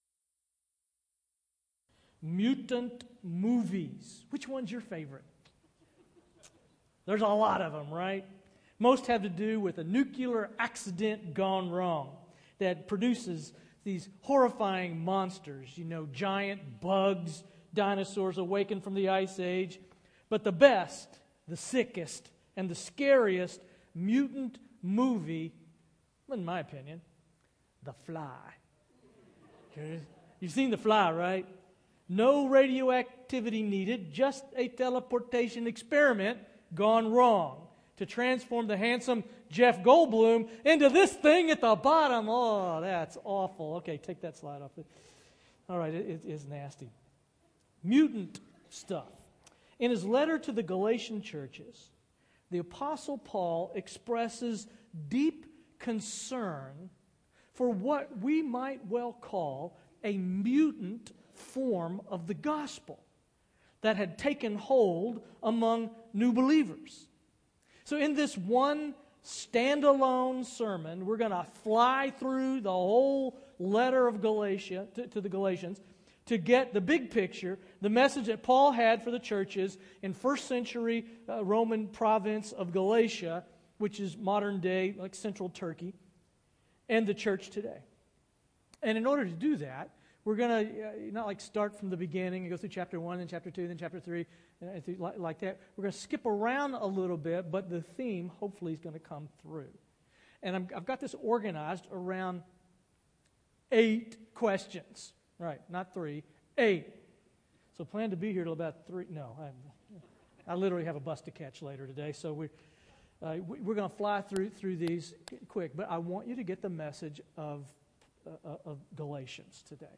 A Message on Galations